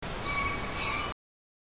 キジ目 キジ科 キジ
忠魂碑で、
鳴き声(mp3:26KB)　 (wav:138KB)
kiji.mp3